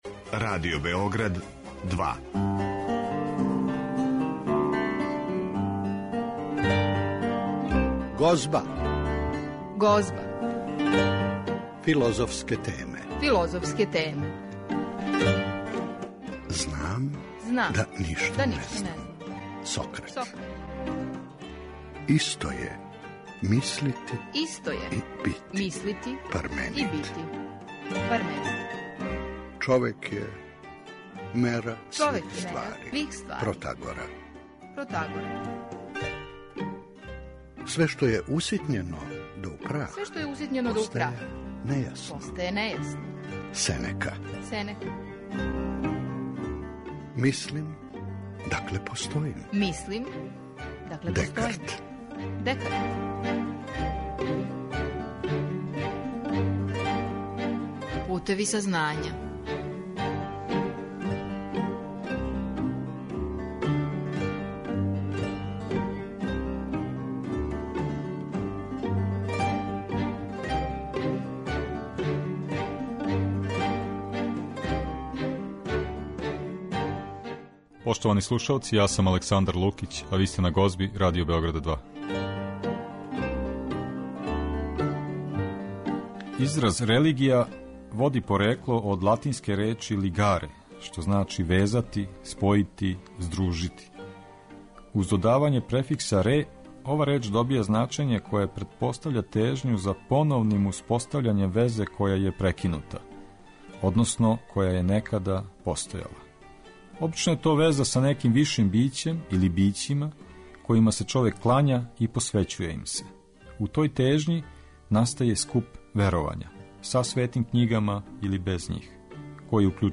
Такође, емитоваћемо и делове излагања неких учесника овог скупа.